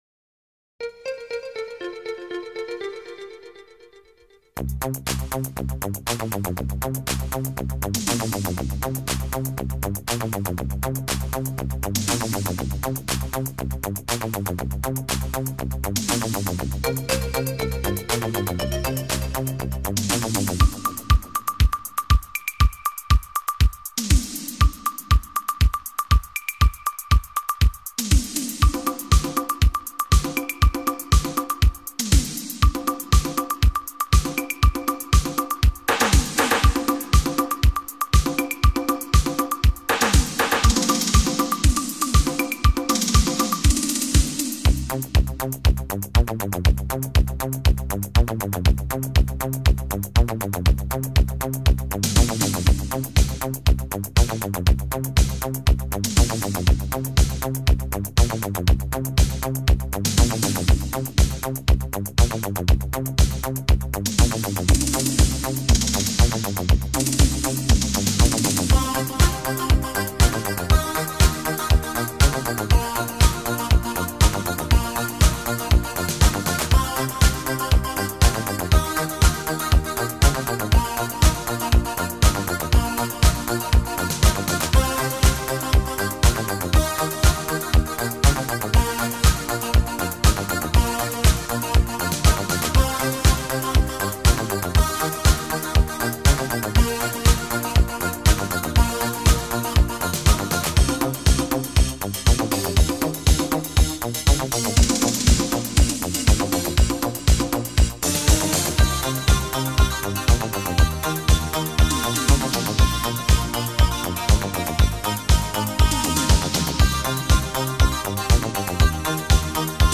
sonoridade futurista e instrumental
Italo-Disco instrumental